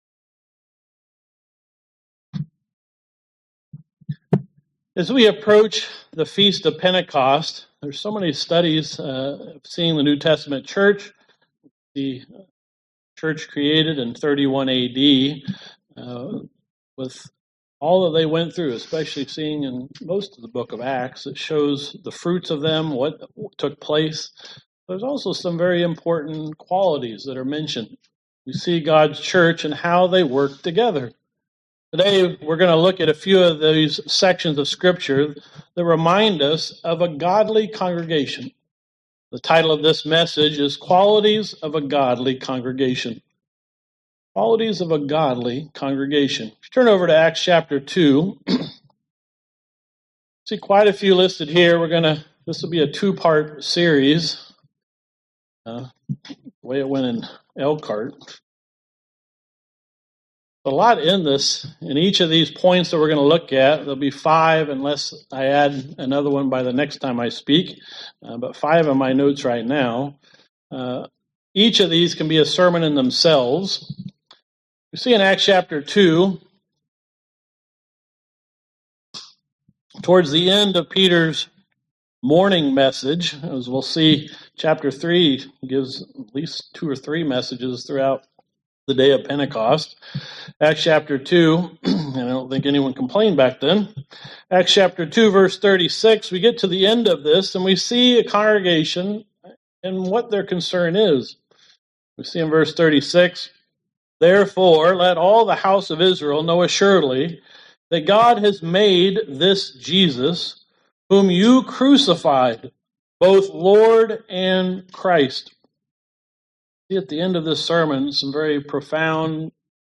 Sermons
Given in Elkhart, IN Northwest Indiana